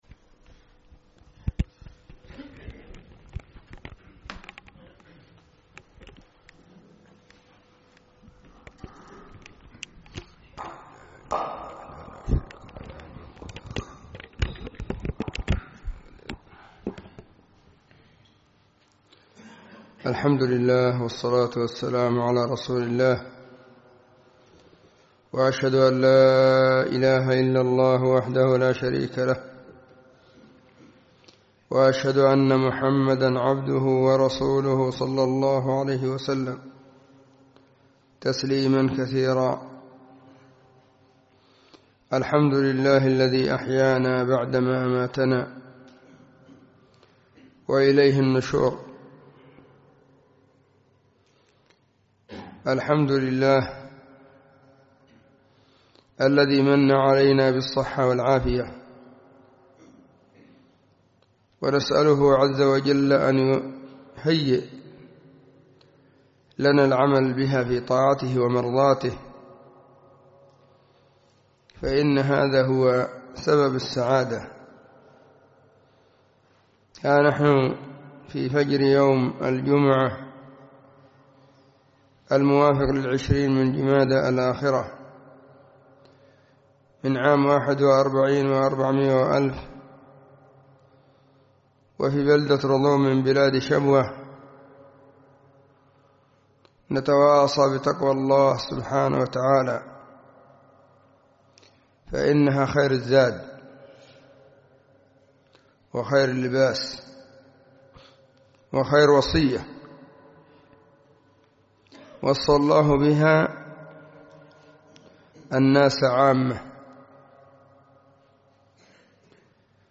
🎙 كلمة بعنوان:بعض صفات المتقين
وكانت بعد – فجر يوم الجمعه – في – رضوم – شبوه –